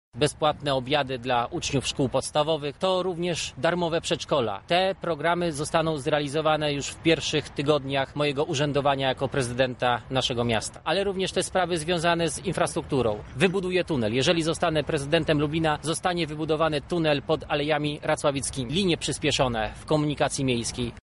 Na jednej z ostatnich konferencji prasowych, Sylwester Tułajew, zachęcał do uczestnictwa w wyborach.
briefing – Tułajew
briefing-Tułajew.mp3